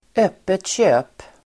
Uttal: [²'öp:et tj'ö:p]